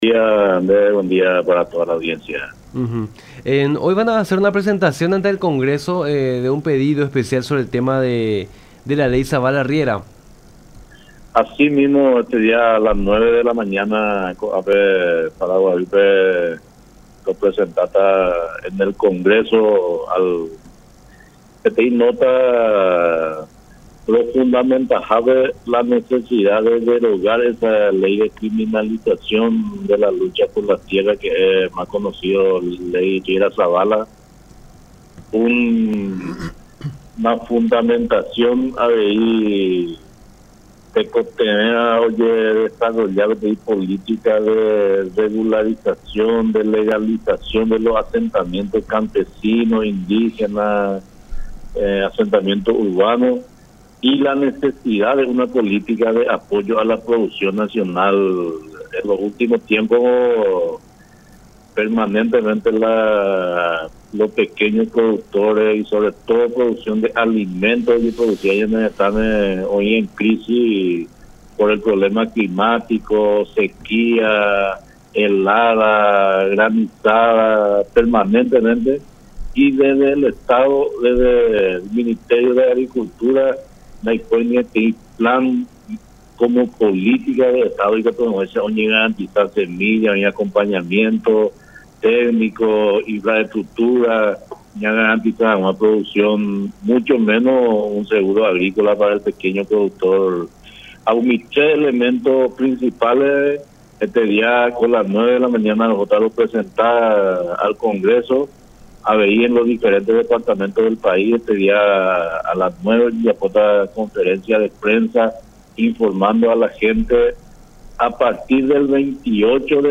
en diálogo con Nuestra Mañana a través de La Unión.